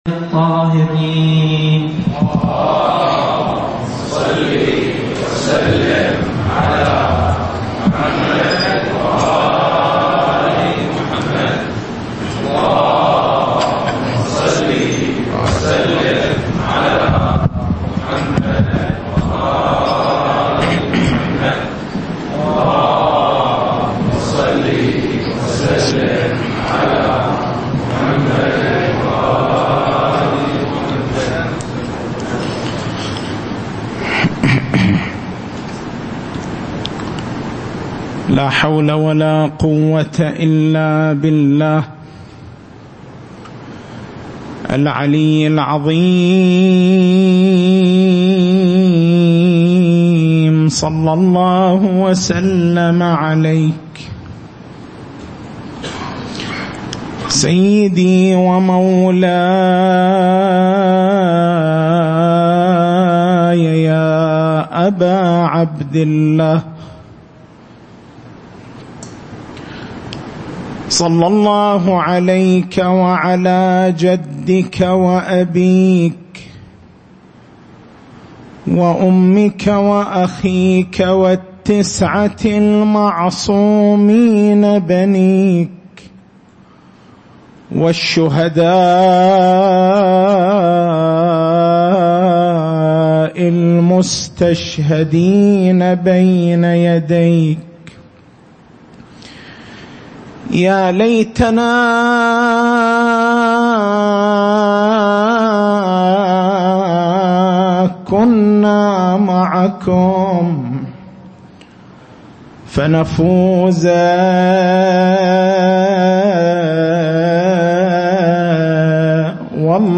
تاريخ المحاضرة: 02/09/1438 محور البحث: لماذا ركّز القرآن الكريم والروايات الشريفة على تكثيف علاقة الإنسان بالدعاء في شهر رمضان المبارك؟